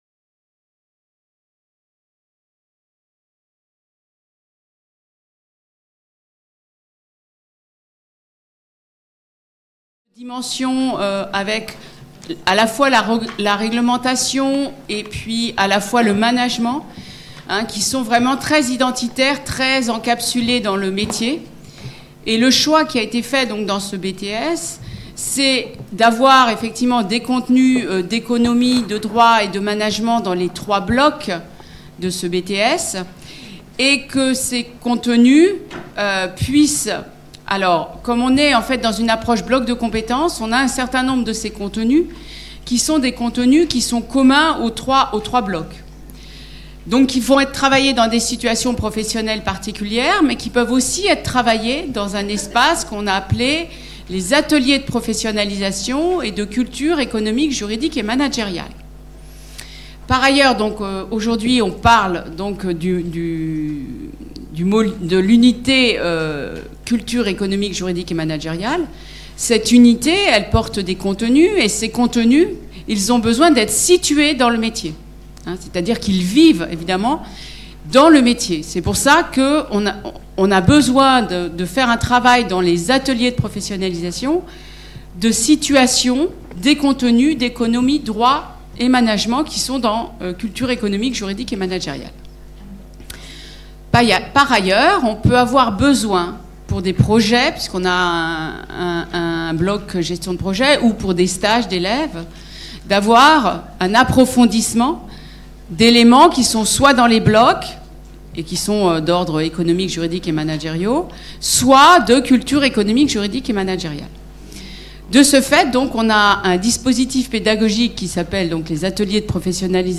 CEJM - 4 - Table ronde : CEJM et articulation avec les domaines professionnels | Canal U